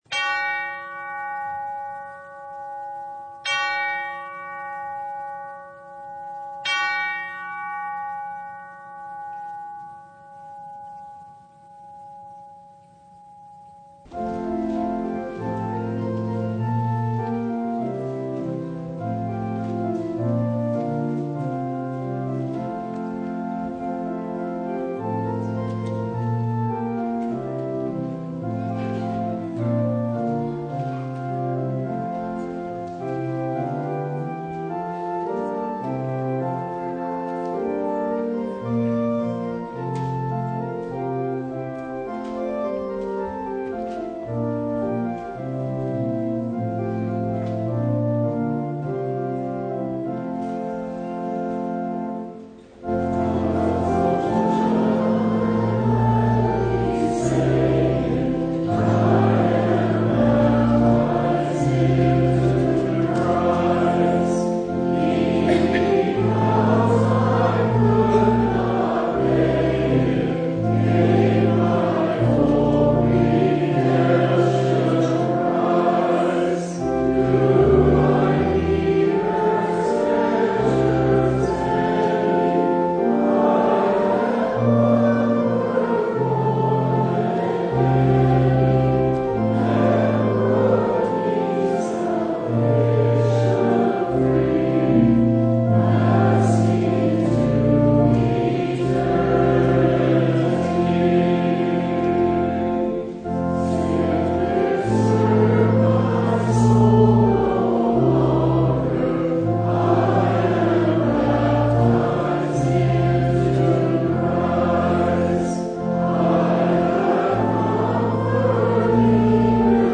Service Type: Service of the Word